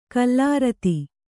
♪ kallārati